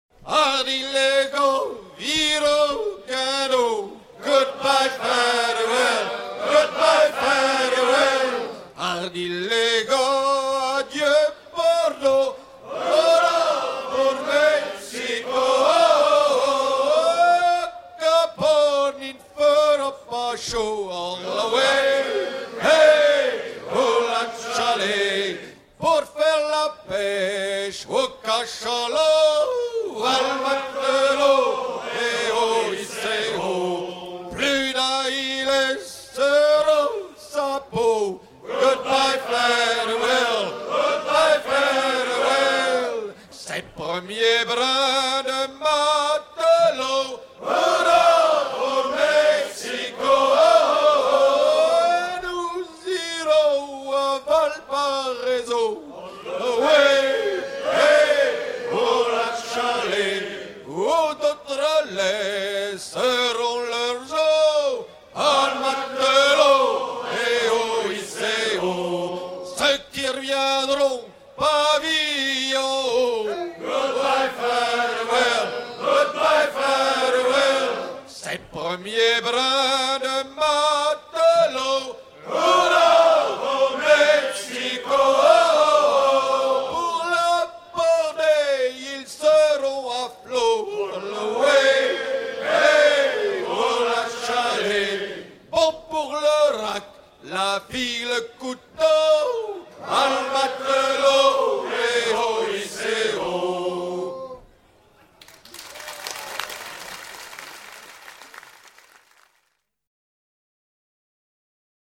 laisse
Pièce musicale éditée